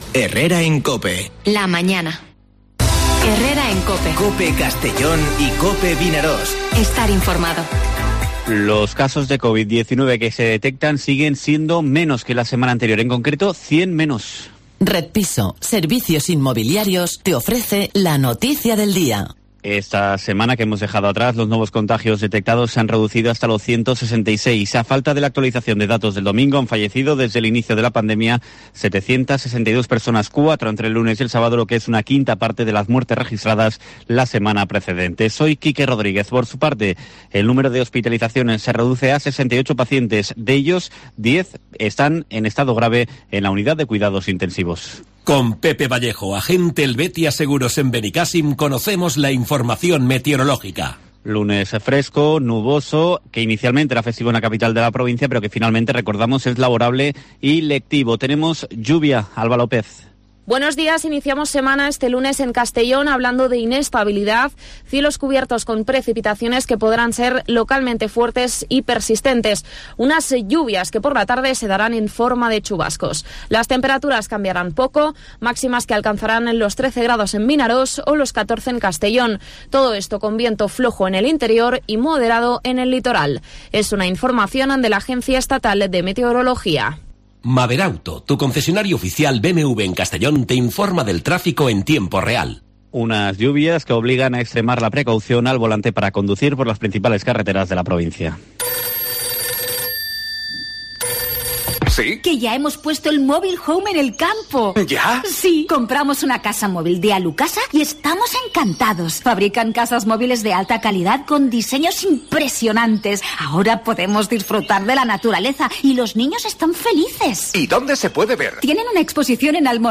Noticias